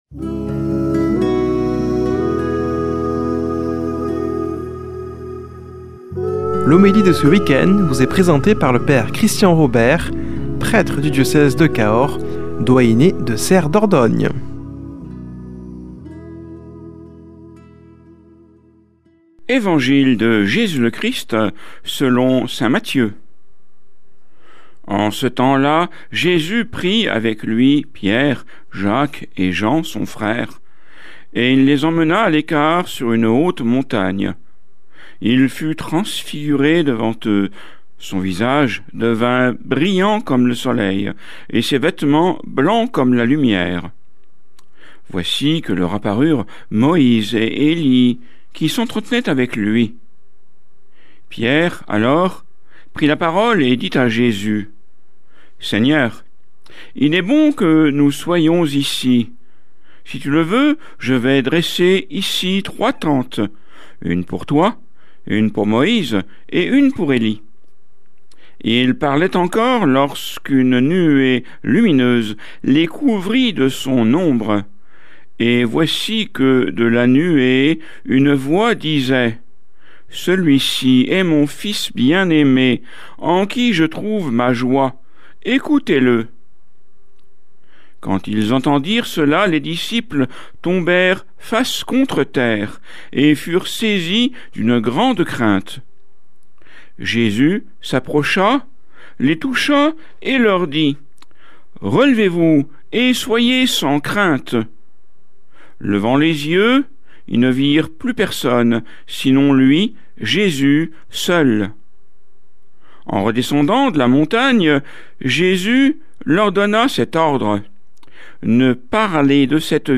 Homélie du 28 févr.